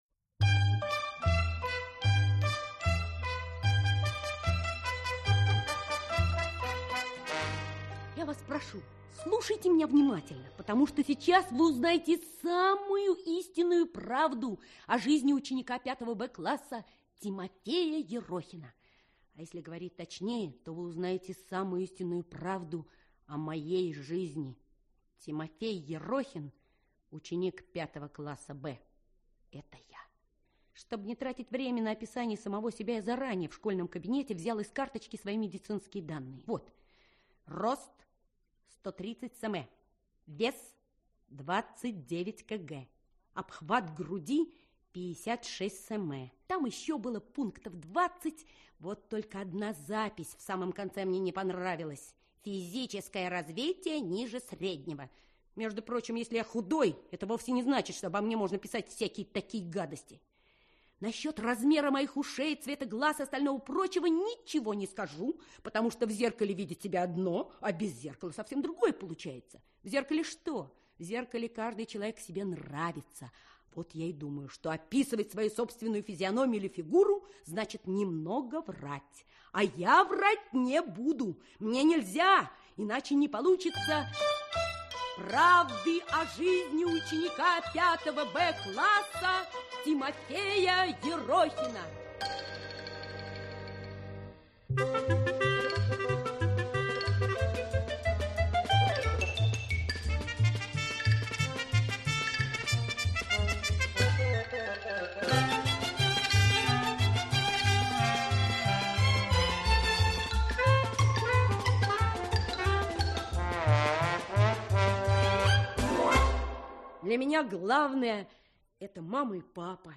Аудиокнига Правда о жизни ученика 5 "Б" класса Тимофея Ерохина | Библиотека аудиокниг